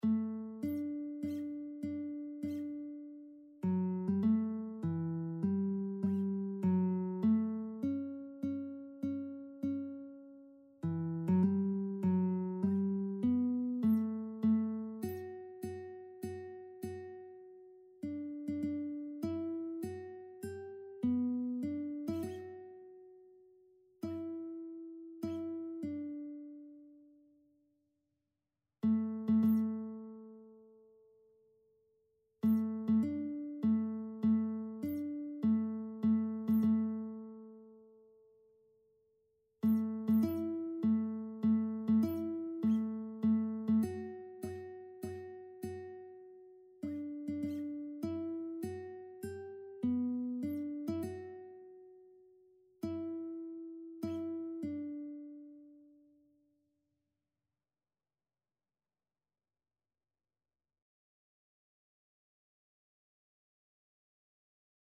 Christian
3/4 (View more 3/4 Music)
Classical (View more Classical Lead Sheets Music)